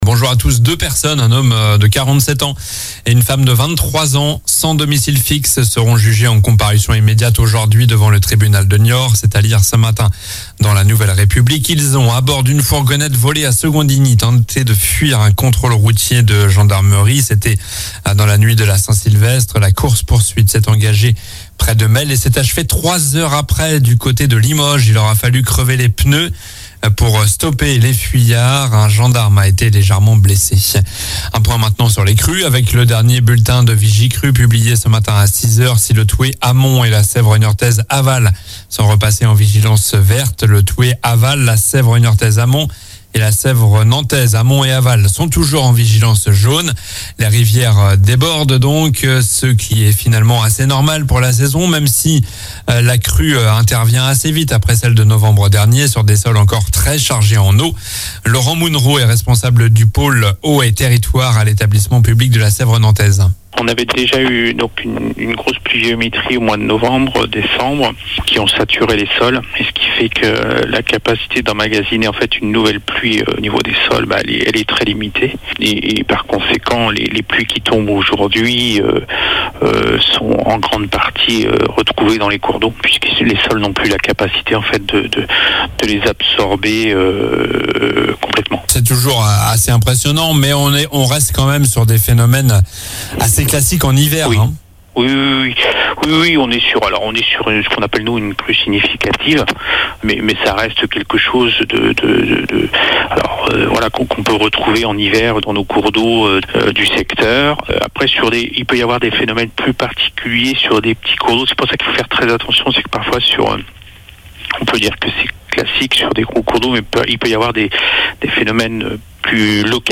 Journal du jeudi 04 janvier (matin)